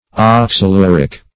Search Result for " oxaluric" : The Collaborative International Dictionary of English v.0.48: Oxaluric \Ox`a*lur"ic\, a. [Oxalyl + urea.]
oxaluric.mp3